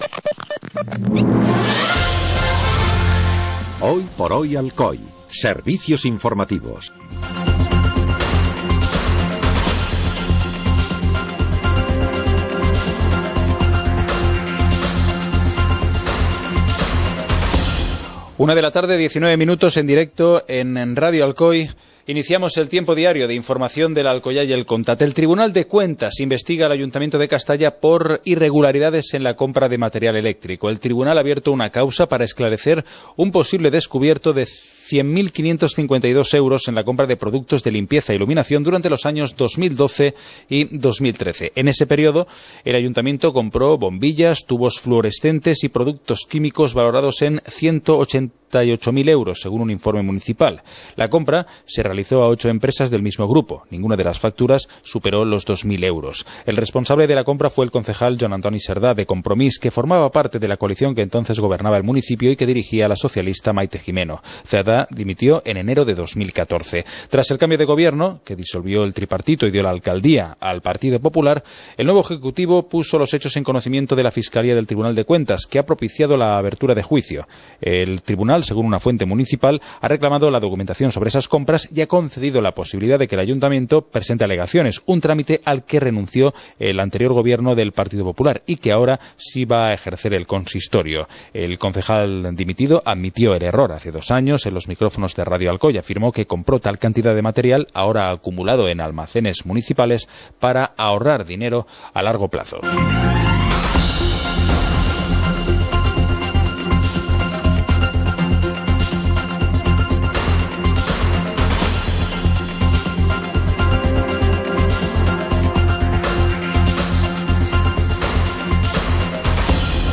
Informativo comarcal - jueves, 14 de enero de 2016